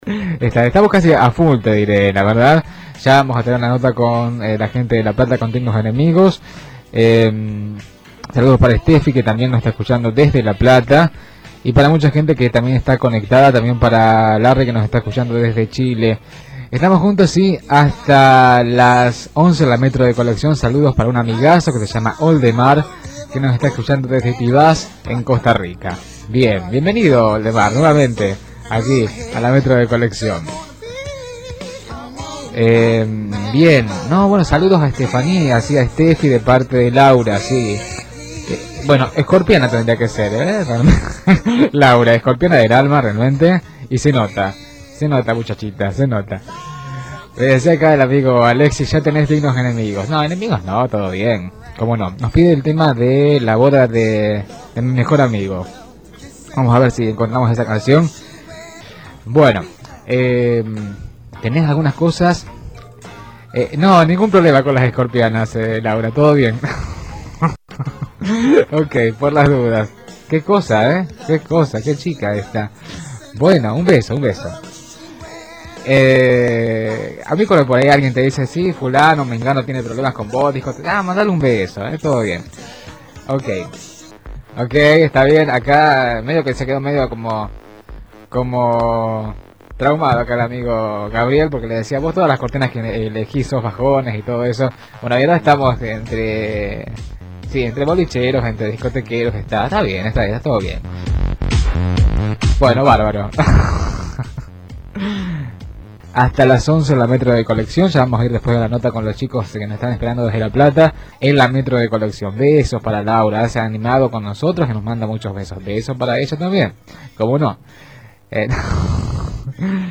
El clima previo vivido en el mismísimo aire de Radio Metropolitana.